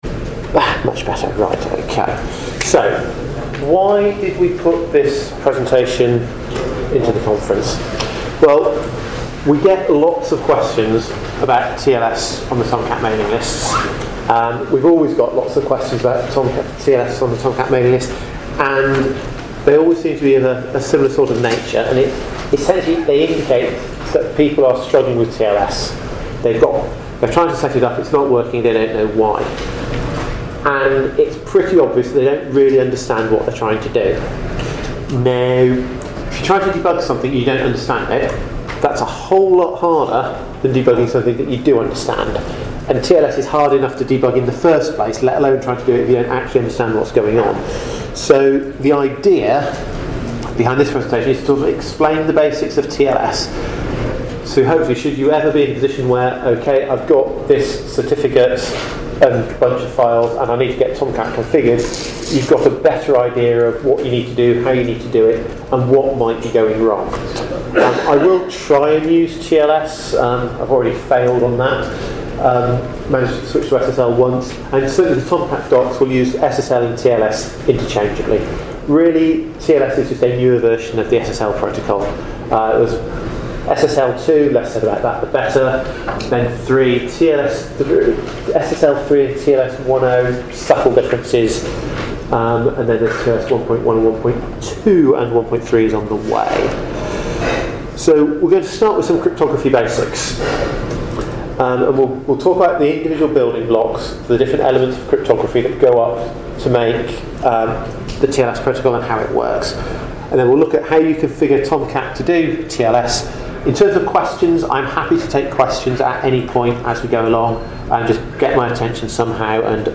ApacheCon Miami 2017 – Apache Tomcat and SSL